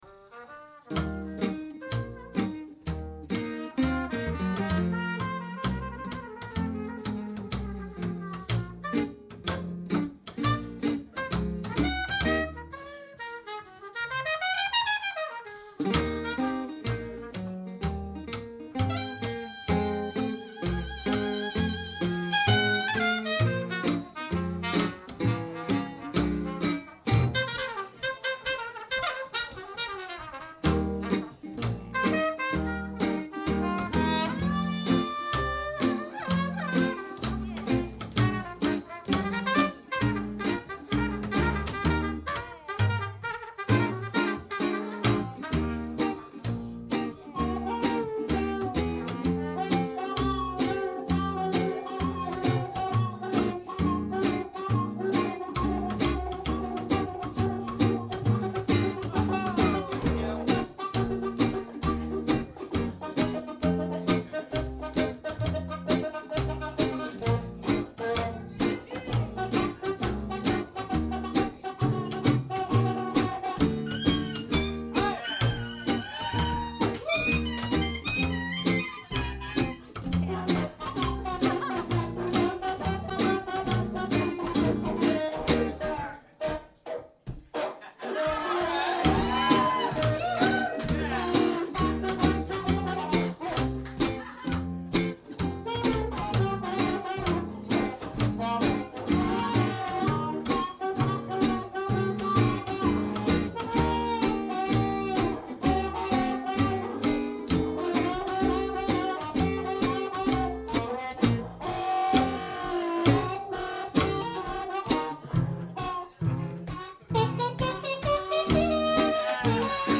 Check out some of their tracks, recorded live @ Banjo Jim's: